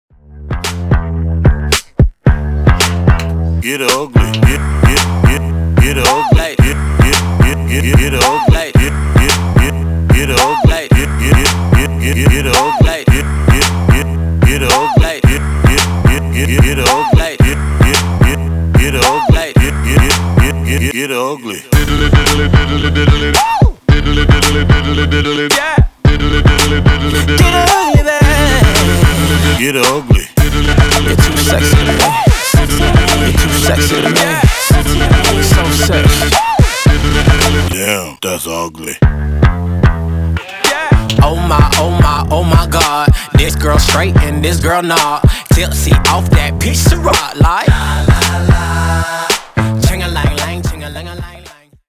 Genres: 80's , RE-DRUM Version: Clean BPM: 128 Time